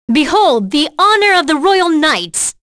Demia-Vox_Skill4.wav